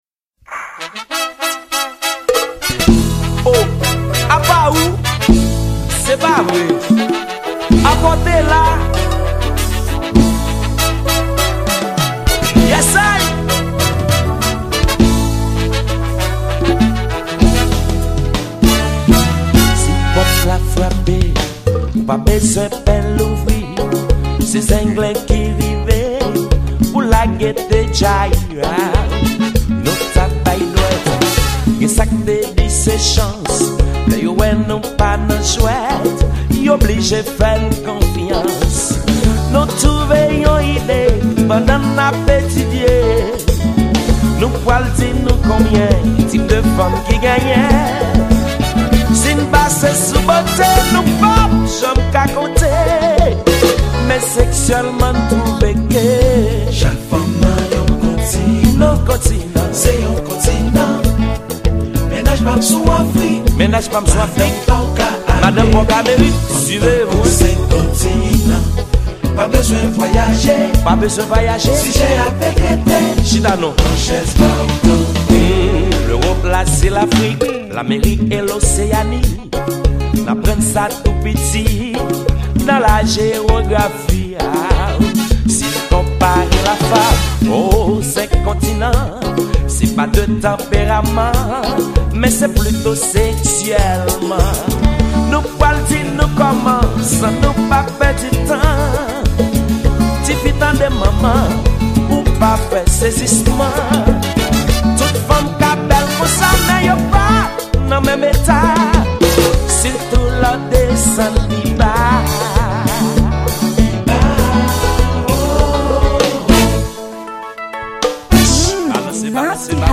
Genre: konpa